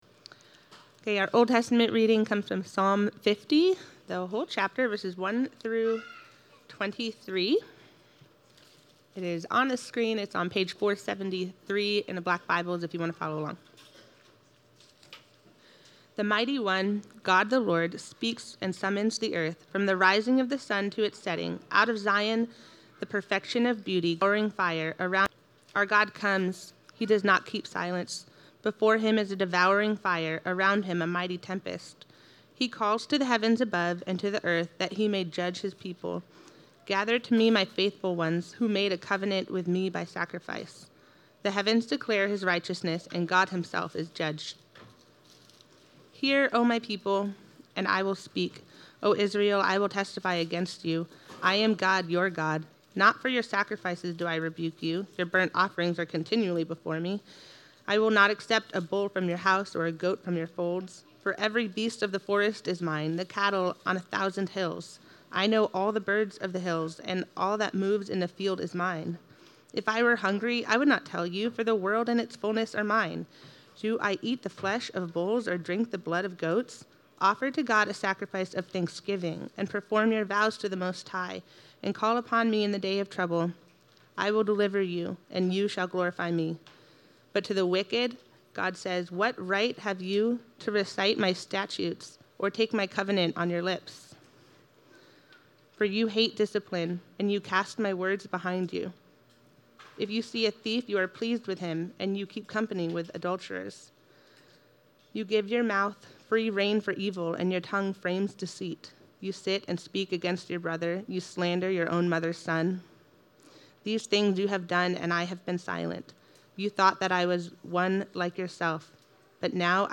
Sermons | Christ Presbyterian Church